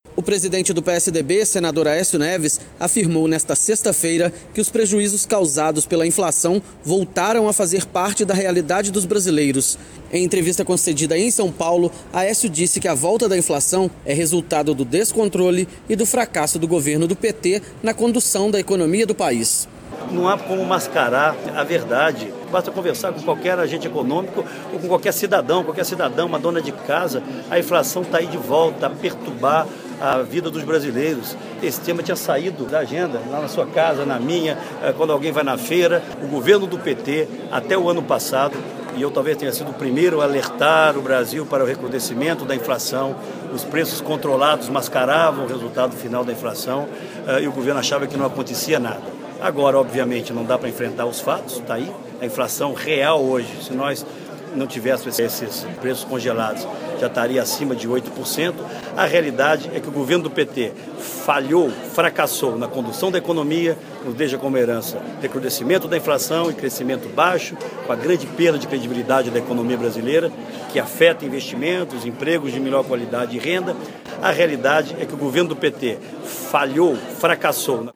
Boletim